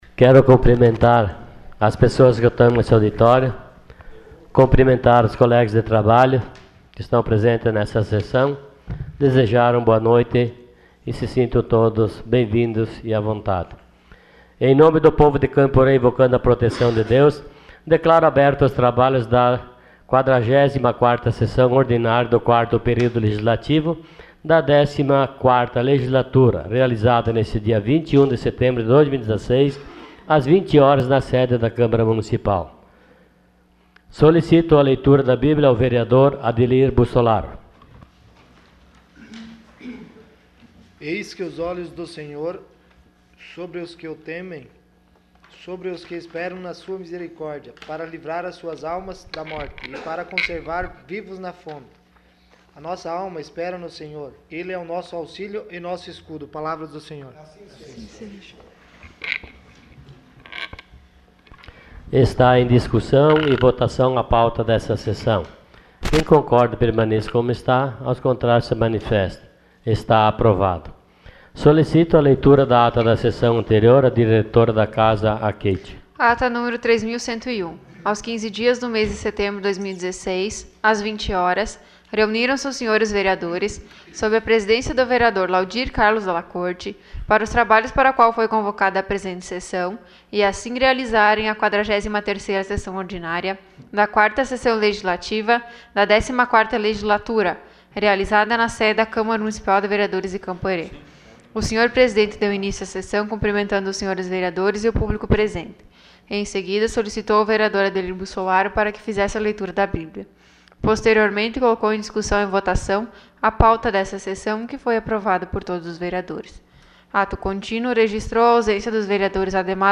Sessão Ordinária dia 21 de setembro de 2016.